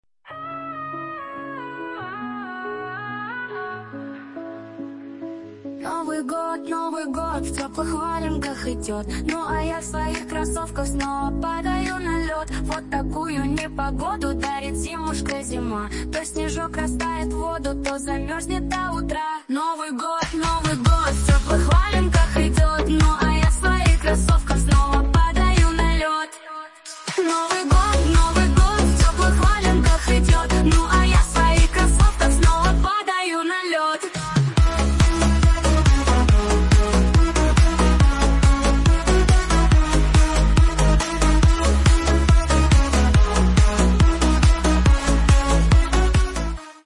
Фрагмент 2 варианта исполнения: